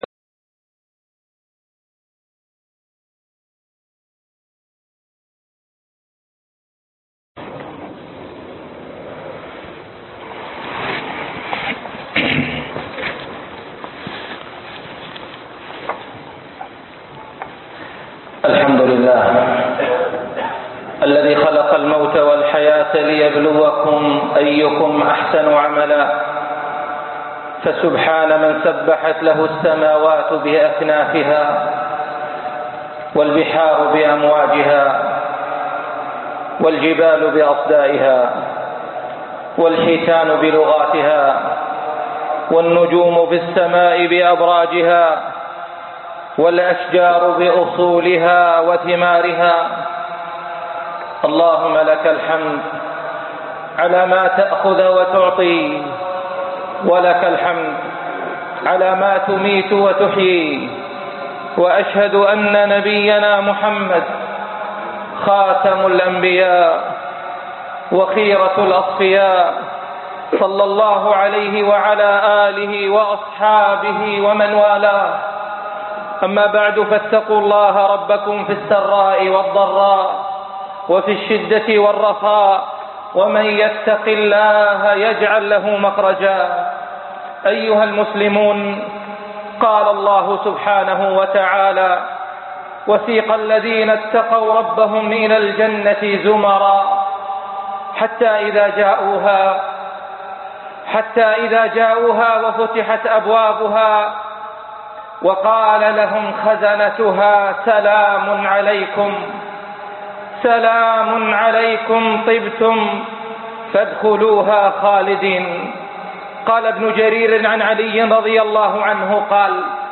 خطب جمعة